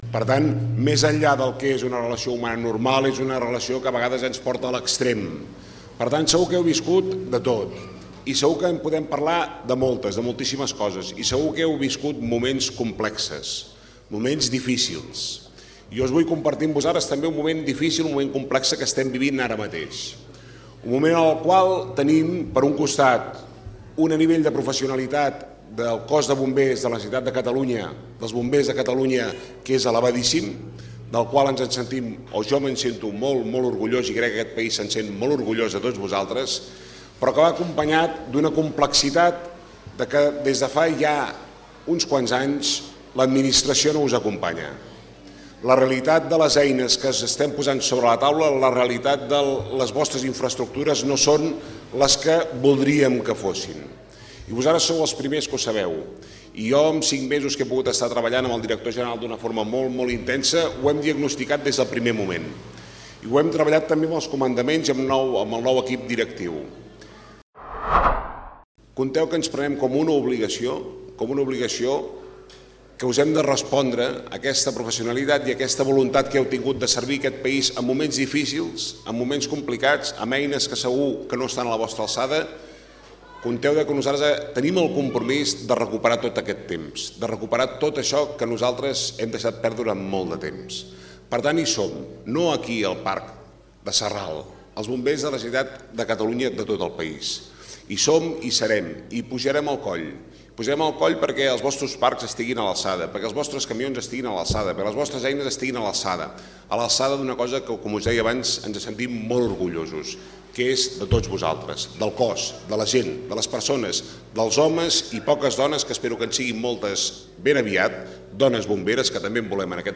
Durant els parlaments, Miquel Buch ha destacat la importància del factor humà dels Bombers de le Generalitat i els voluntaris que, assegura, ha estat clau per superar moments complexos.